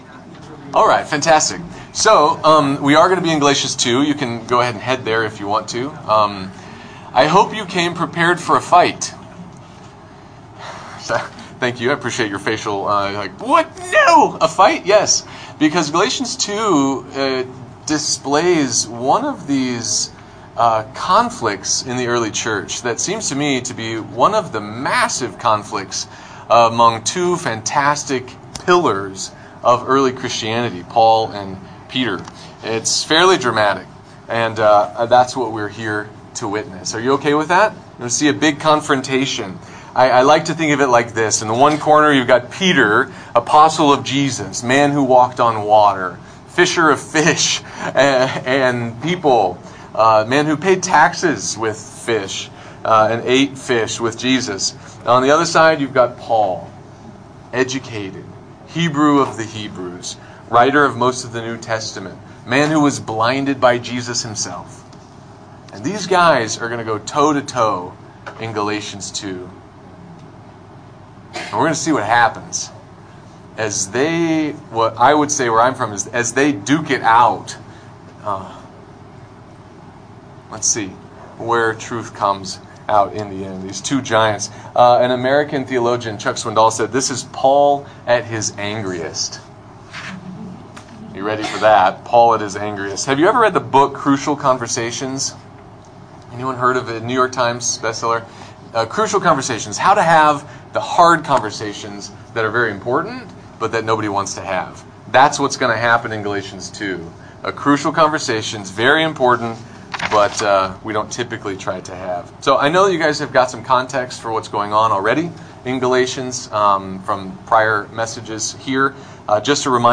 sermon.icf_.march-2020-galatians-2.mp3